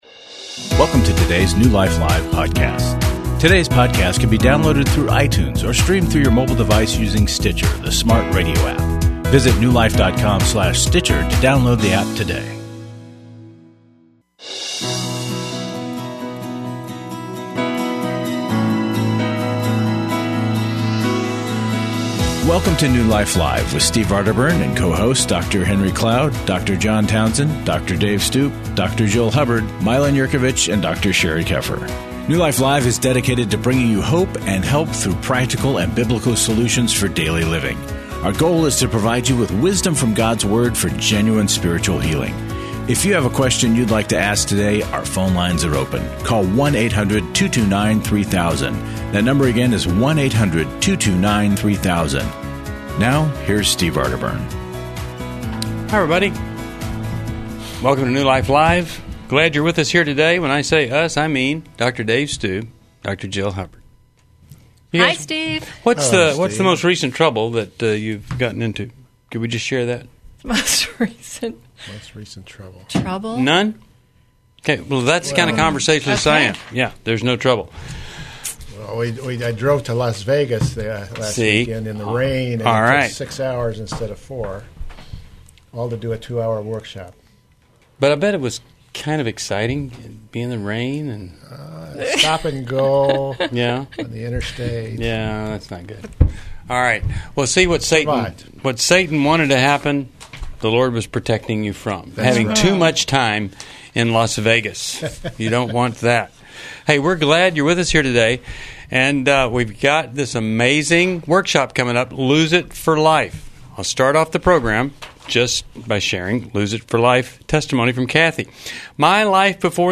Caller Questions: I was horrible to my family while I was addicted.